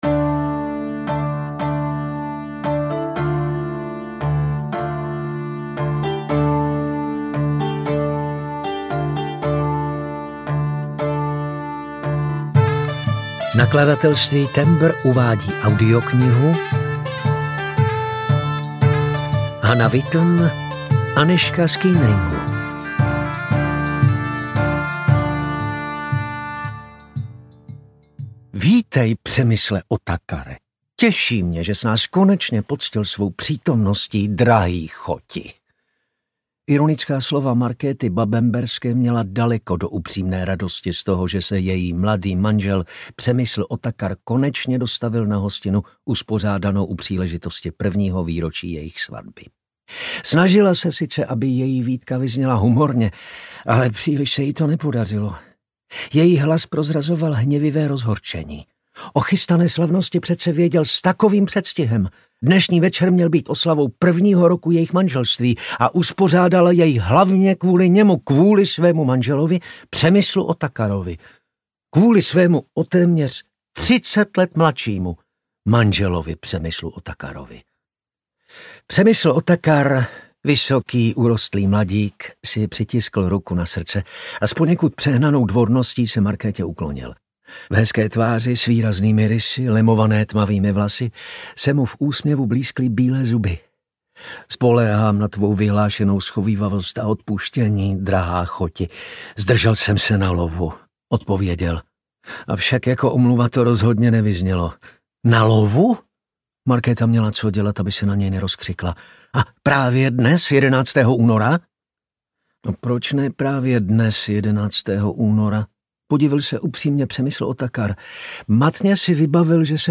Anežka z Kuenringu audiokniha
Ukázka z knihy